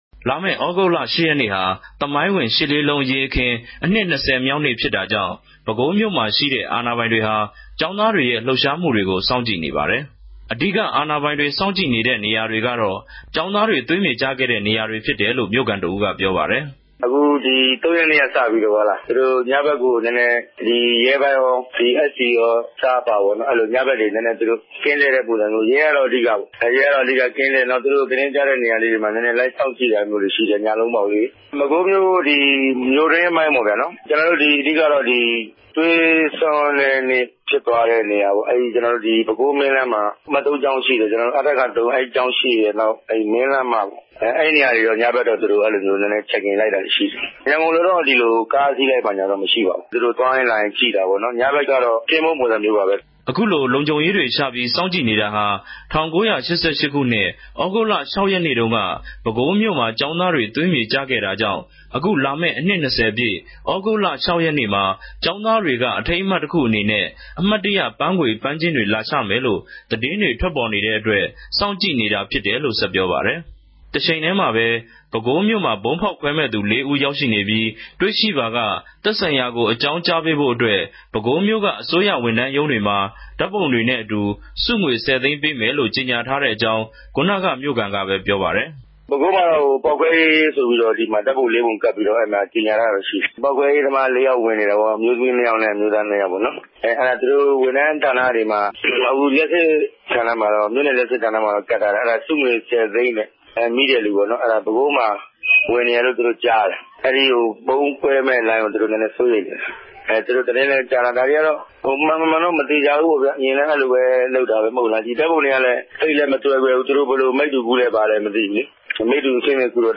သတင်းပေးပိုႛခဵက်။